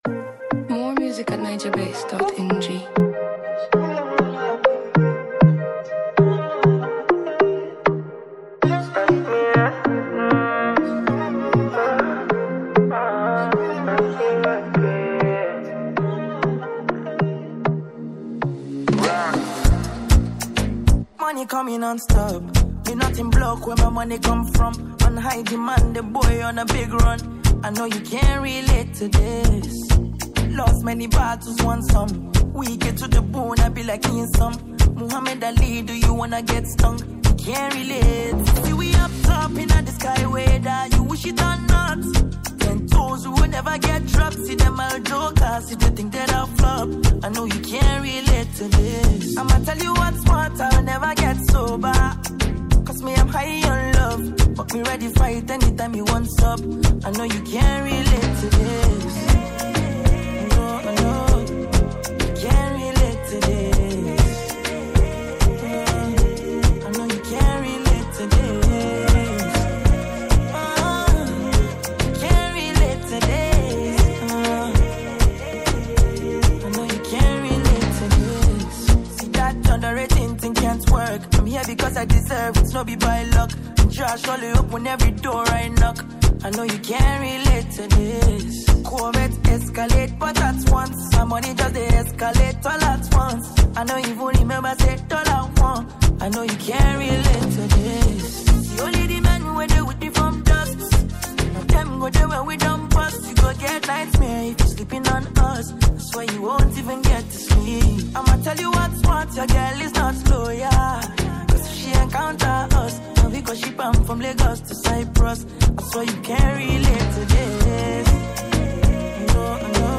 Afrobeats
smooth melodies and catchy Afrobeats rhythms
With its powerful lyrics and vibrant sound